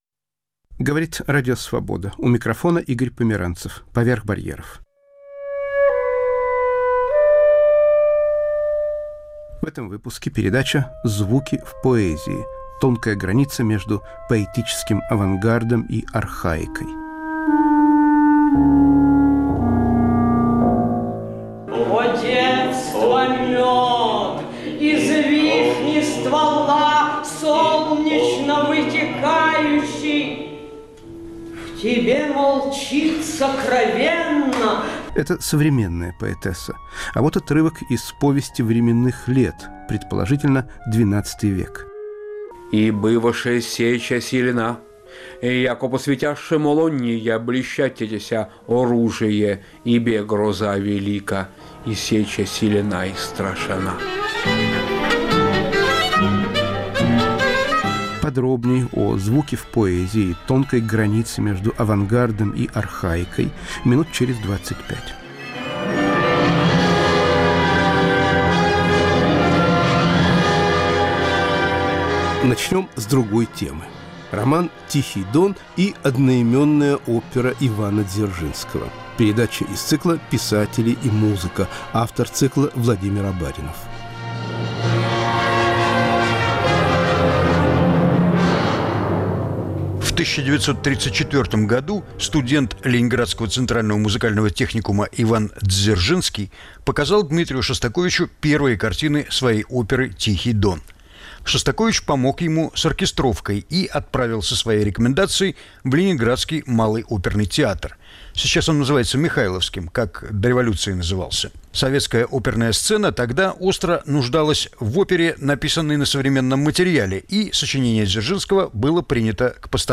В передаче звучат голоса К.Чуковского, А.Кручёных, С.Кирсанова и др.